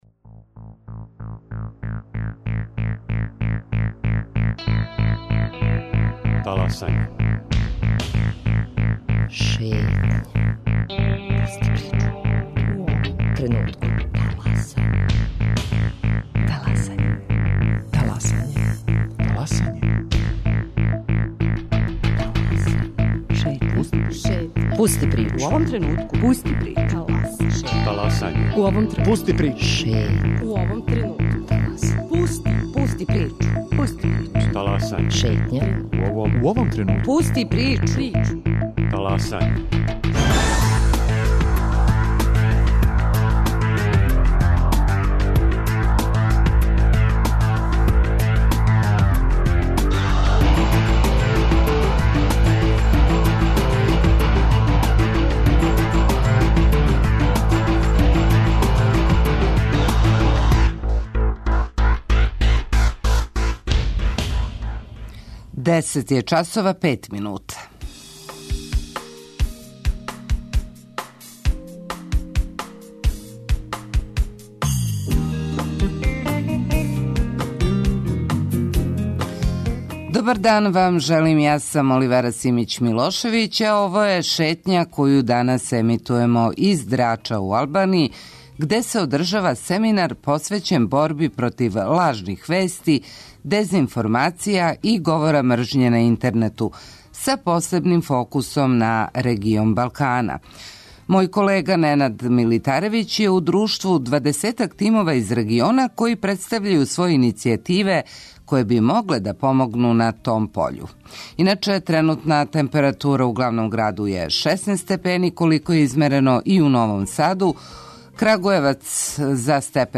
Емисију емитујемо из Драча, где је у току састанак балканских организација цивилног друштва посвећен борби против лажних вести и говора мржње на интернету.